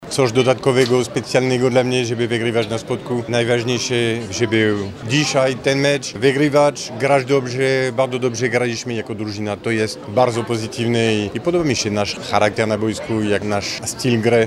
– stwierdził szkoleniowiec Bogdanki LUK Lublin, Stephane Antiga.